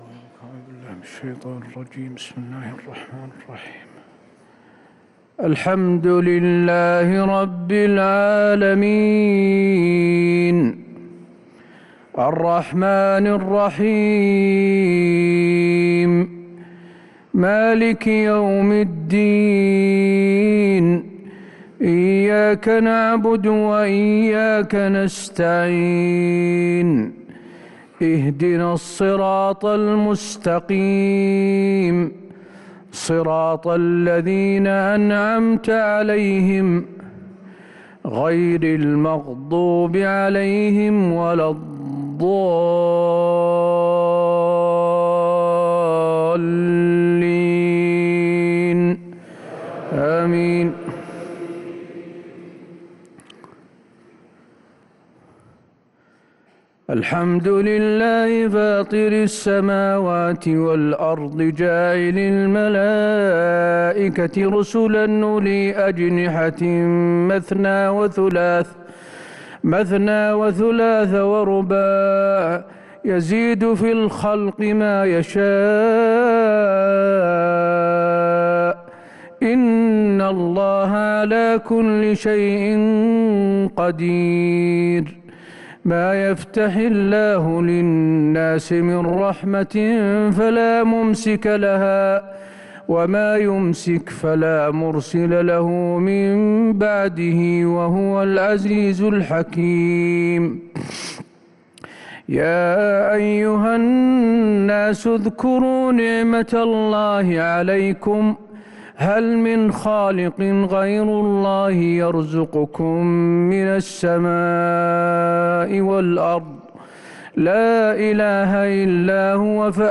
صلاة العشاء للقارئ حسين آل الشيخ 18 ذو الحجة 1443 هـ